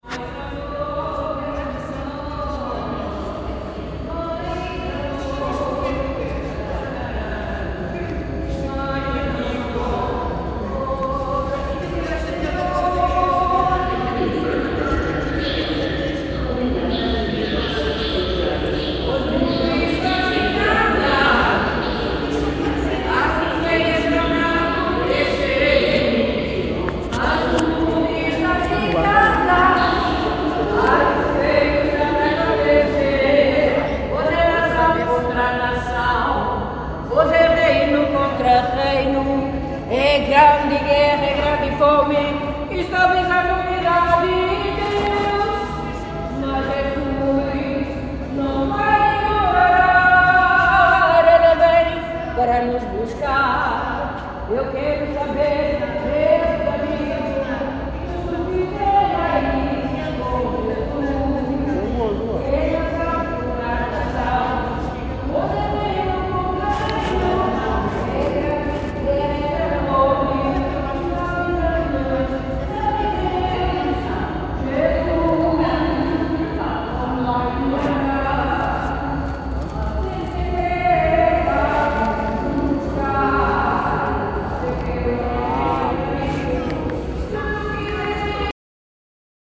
Metro singer, Lisbon